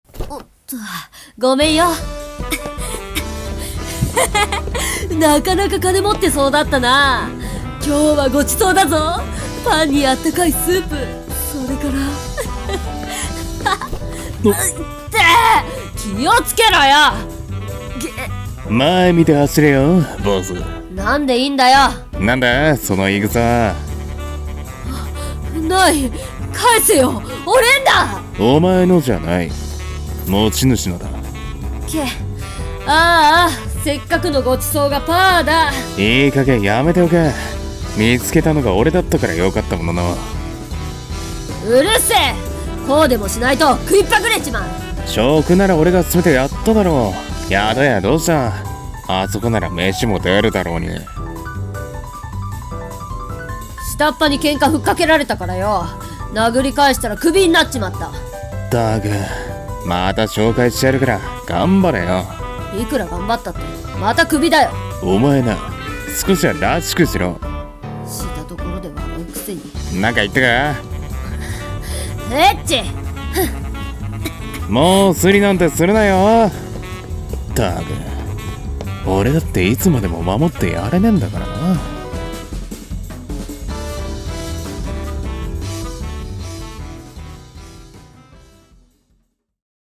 【声劇】今日も今日とて【2人声劇】